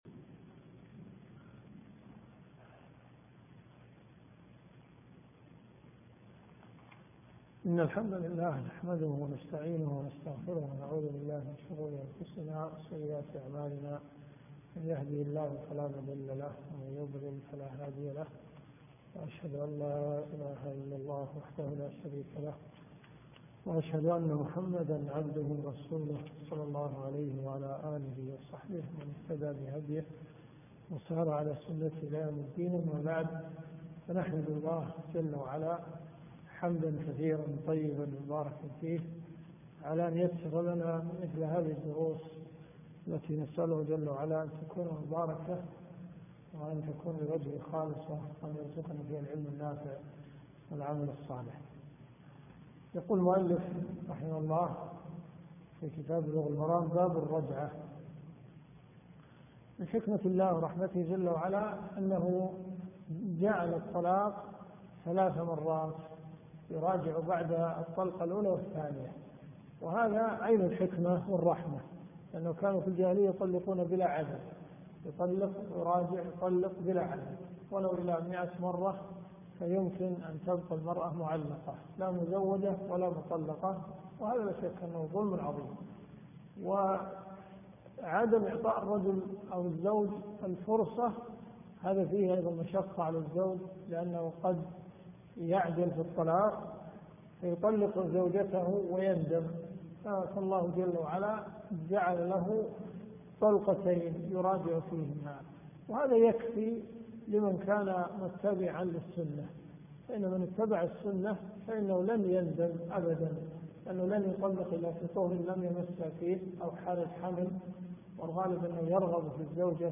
الرياض . حي النخيل . جامع الاميرة نورة بنت عبدالله
الرئيسية الدورات الشرعية [ قسم أحاديث في الفقه ] > بلوغ المرام . 1431 .